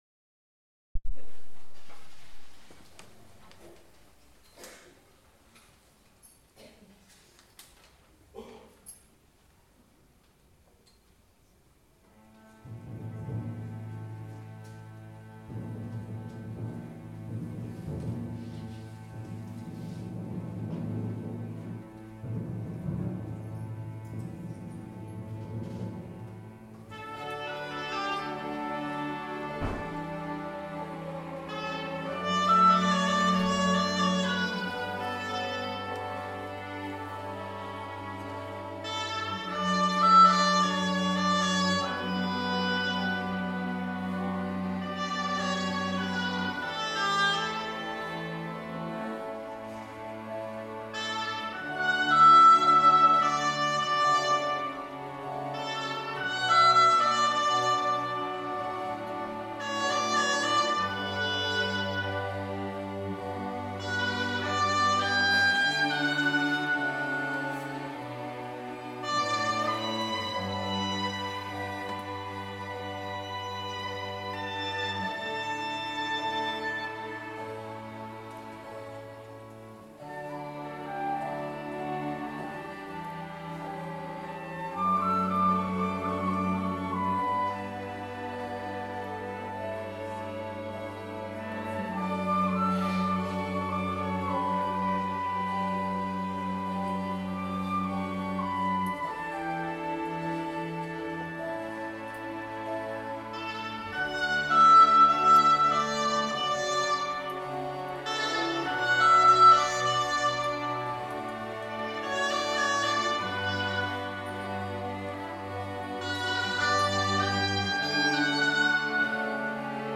Orchestra 2